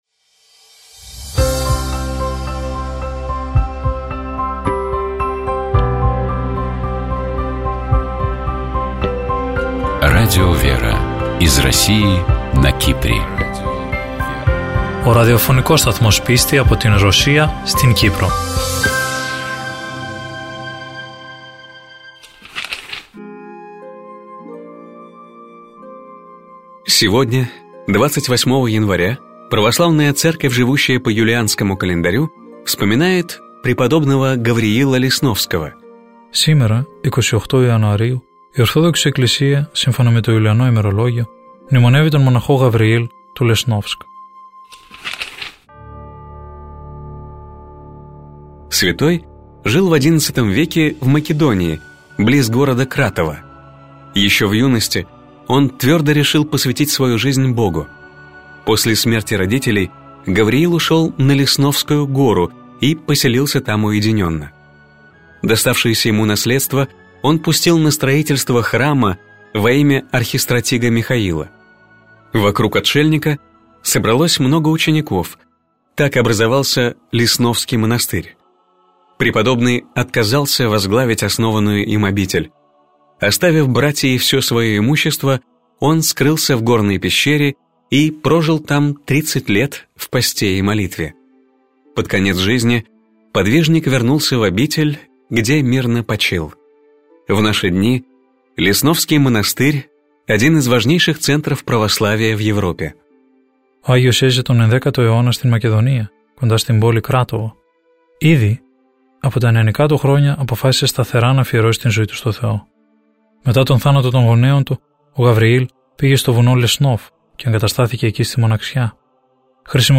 По благословению митрополита Лимассольского Афанасия (Кипрская Православная Церковь) в эфире радио Лимассольской митрополии начали выходить программы Радио ВЕРА. Популярные у российского слушателя программы переводятся на греческий язык и озвучиваются в студии Радио ВЕРА: «Православный календарь», «Евангелие день за днем», «Мудрость святой Руси», «ПроСтранствия», «Частное мнение» и другие.